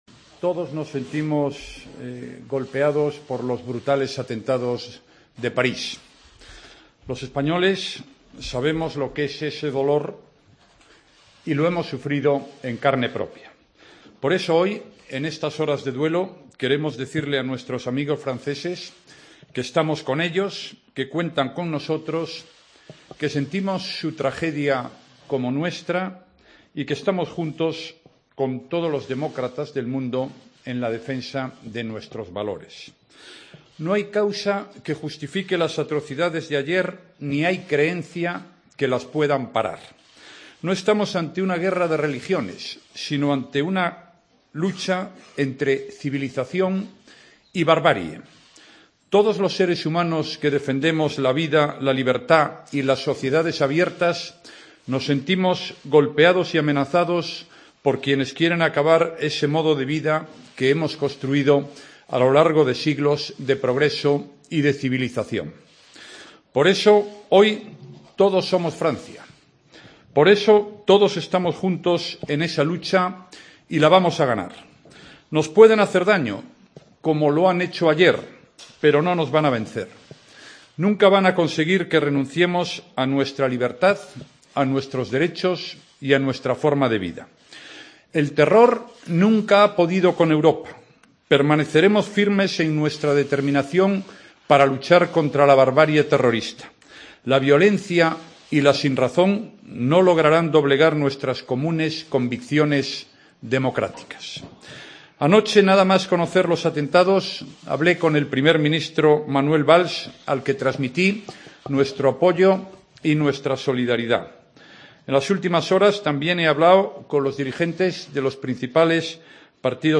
Comparecencia de Mariano Rajoy por los atentados de París